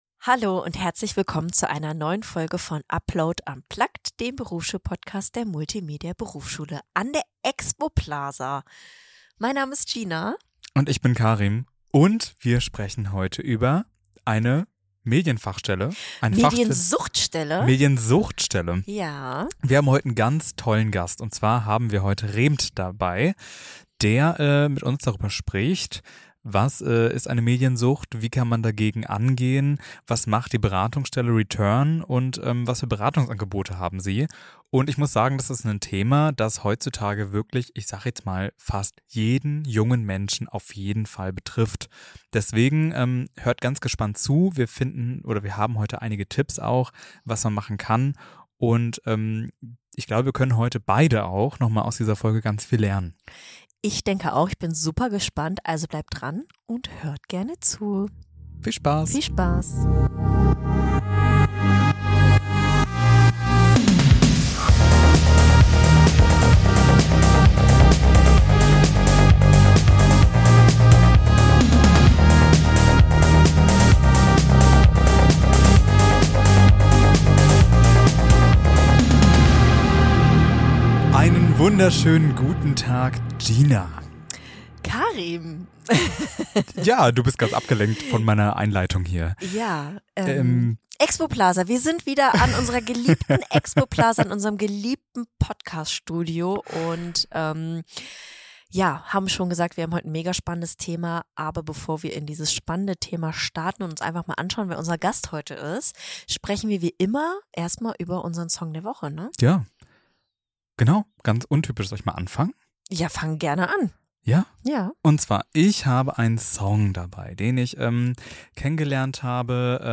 Ein ehrliches Gespräch über digitale Gewohnheiten, Suchtgefahren und Wege zurück in die Balance.